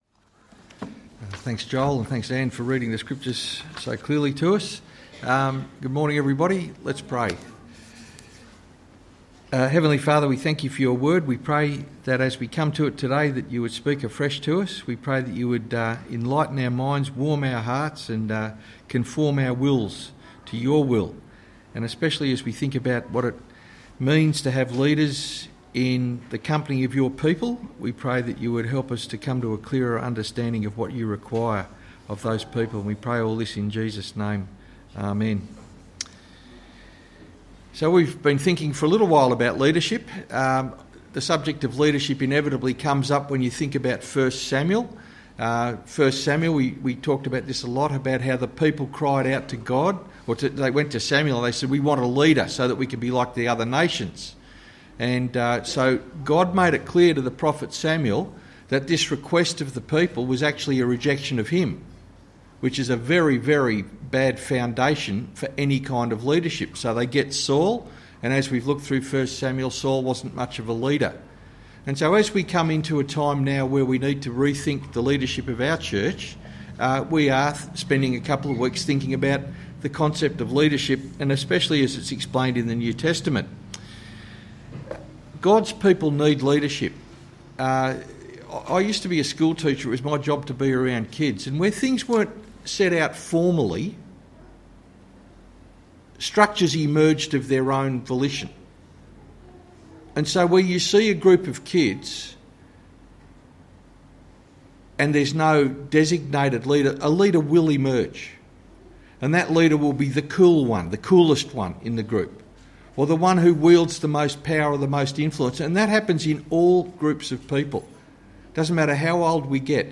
Luke 22:24-30 Sermon